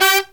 HIGH HIT04-L.wav